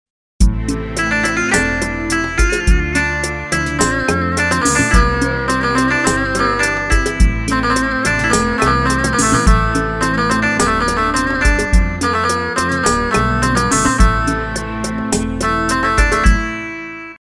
Telli Çalgilar Samples
Asagida Dinlediginiz Sample Sesleri direk Orgla Calinip MP3 Olarak Kayit edilmistir
Elektro Dörtteilli 1